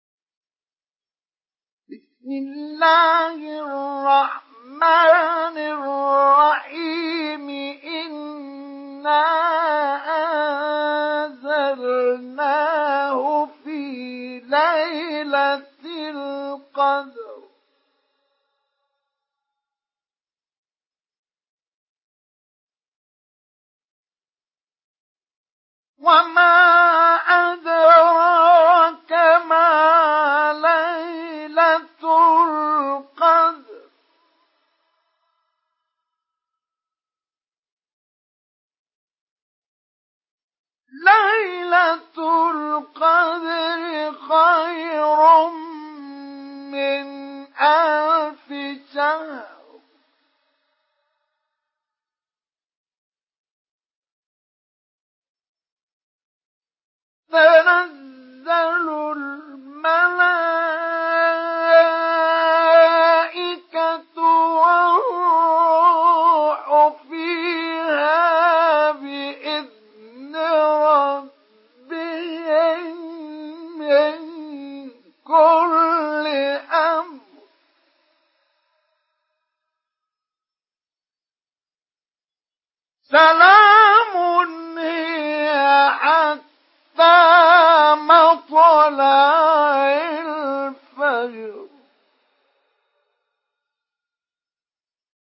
Surah Al-Qadr MP3 in the Voice of Mustafa Ismail Mujawwad in Hafs Narration
Surah Al-Qadr MP3 by Mustafa Ismail Mujawwad in Hafs An Asim narration.